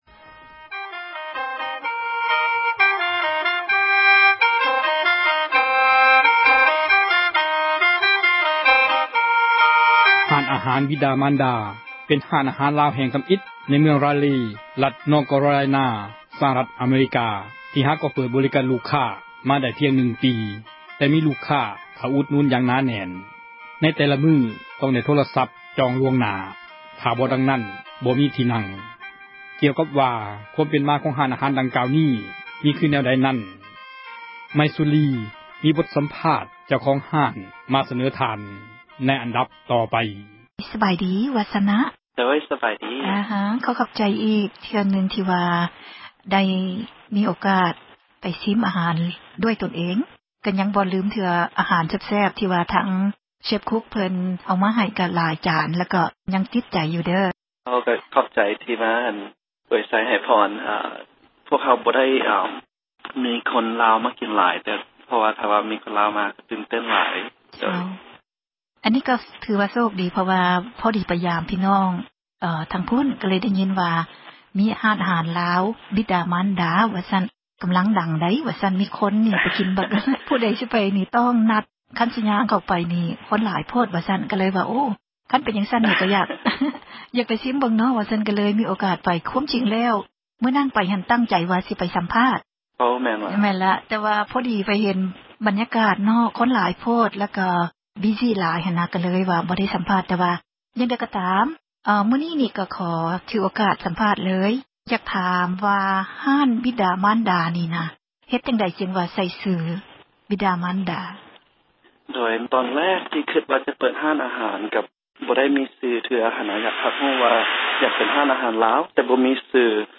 ສັມພາດເຈົ້າຂອງຮ້ານອາຫານ ຊື່ດັງ